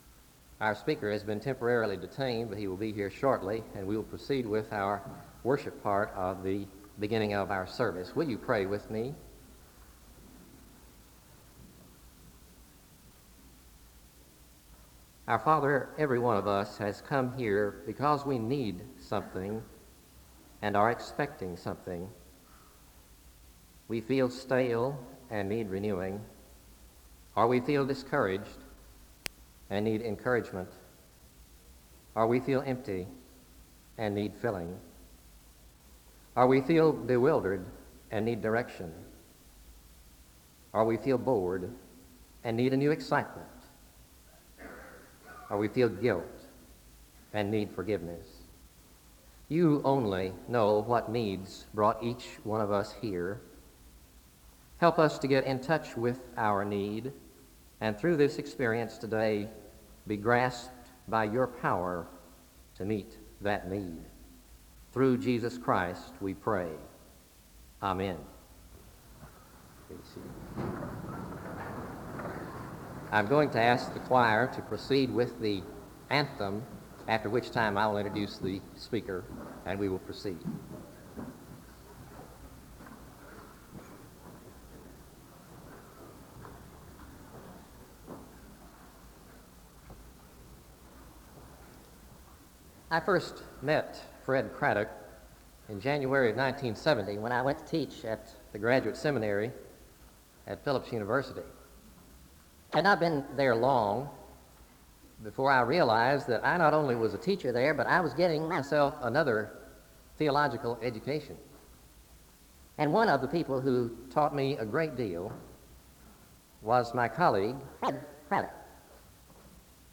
A word of prayer is given (0:00-1:08). An anthem is sung (cut) (1:09-1:31).
A hymn ends the service (37:31-37:42; the hymn is cut abruptly).